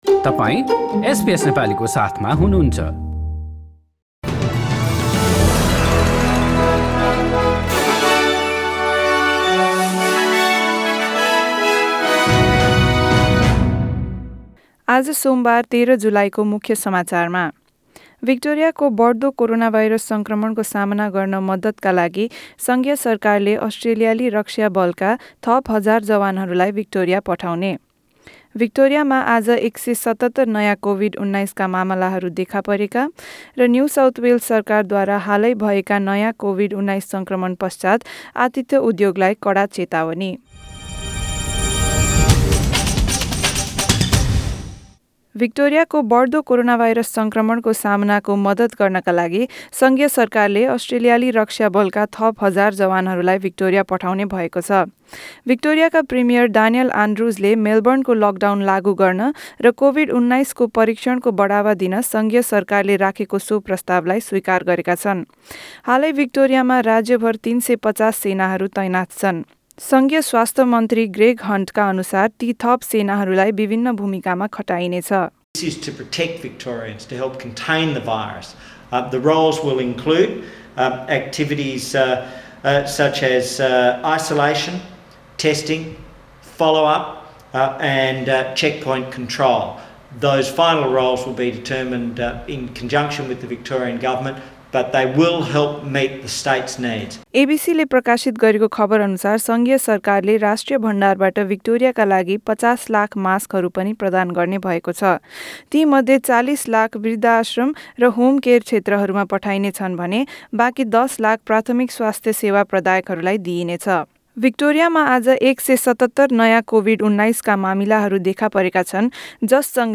SBS Nepali Australia News: Monday 13 July 2020